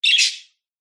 На этой странице собраны натуральные звуки чириканья птиц в высоком качестве.
Чирик-чирик